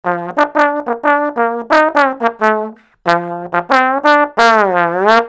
Trombone.mp3